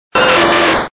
Fichier:Cri 0126 DP.ogg